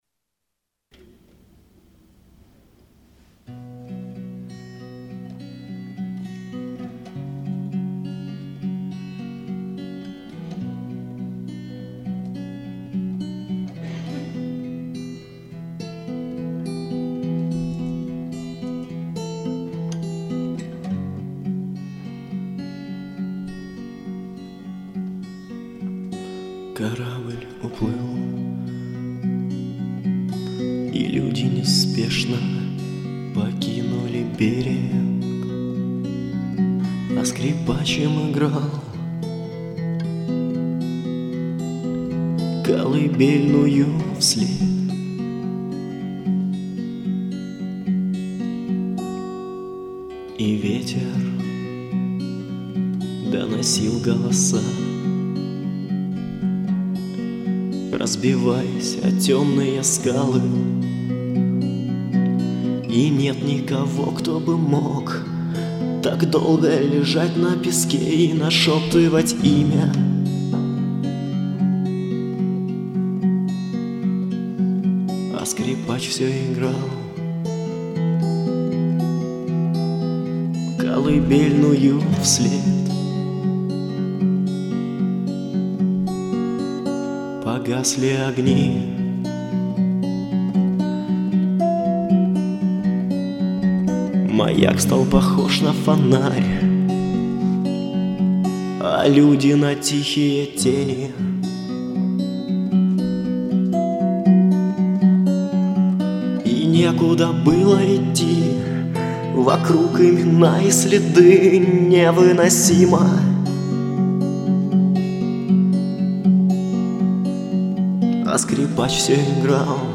Кому интересно дослушает до конца интересно каждое мнение это первое музыкальное произведение записанное на студии.